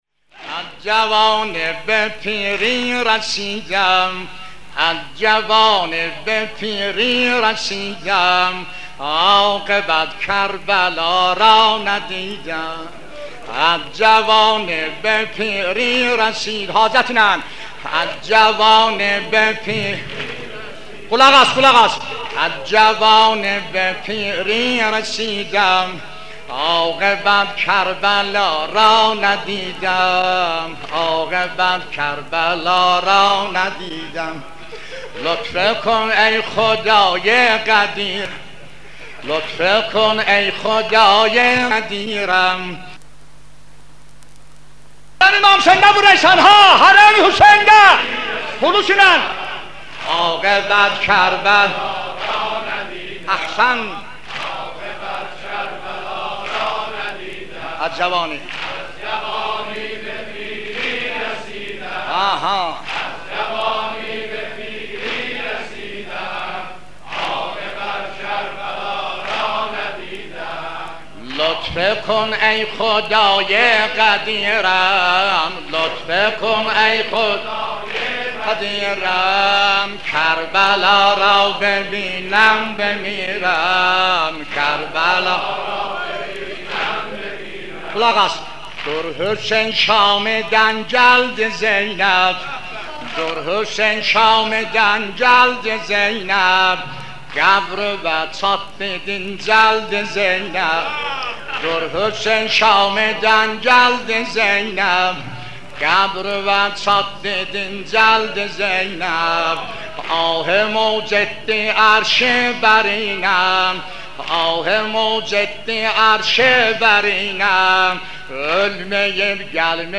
مداحی شنیدنی استاد سلیم موذن زاده اردبیلی